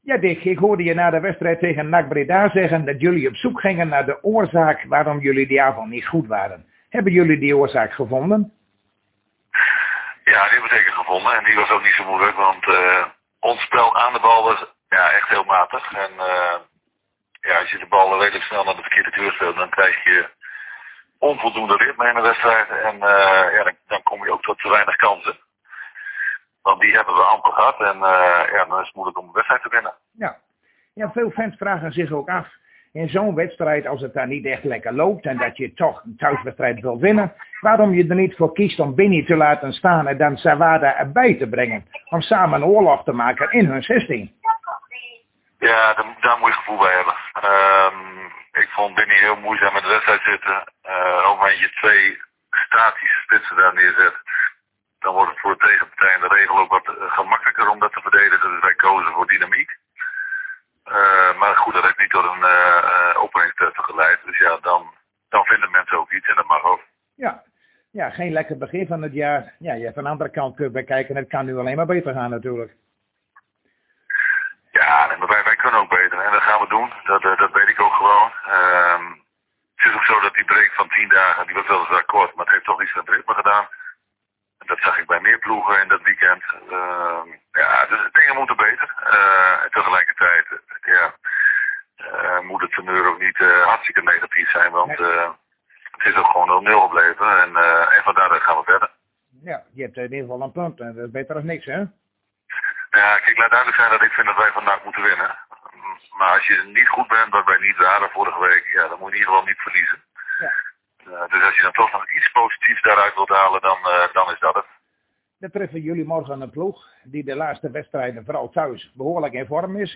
Zojuist spraken wij weer met trainer Dick Lukkien van FC Groningen over de wedstrijd van morgen tegen SC Heerenveen en we plaatsten ook een aantal foto's van de training.